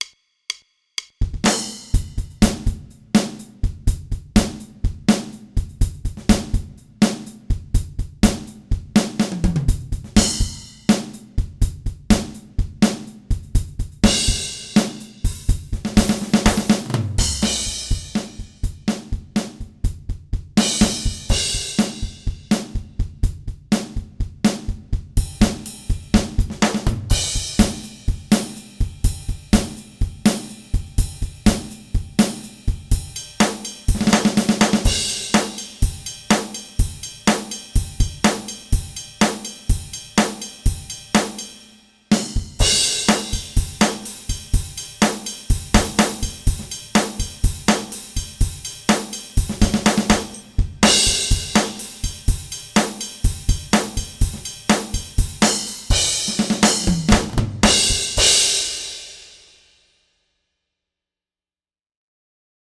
Mapex dob SF2: